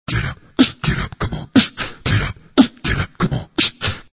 misc_vocal00.mp3